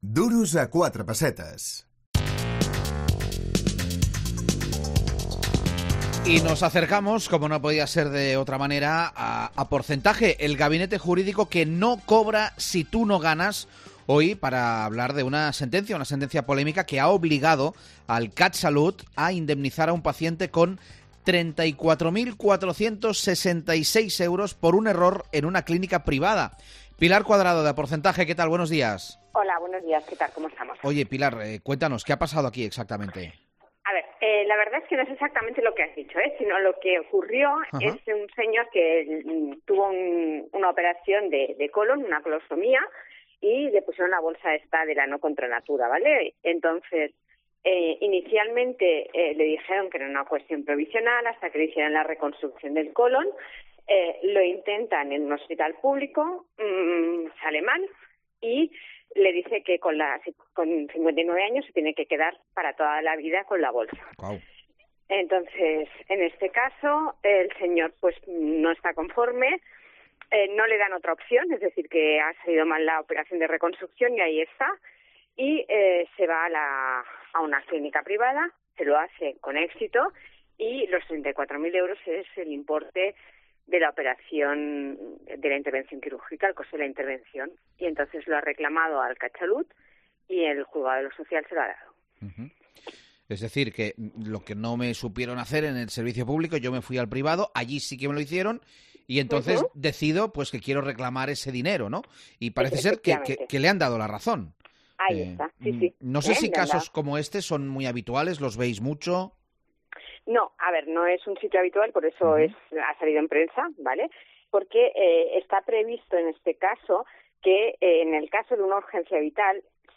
Parlem d'aquest cas amb el gabinet jurídic Aporcentaje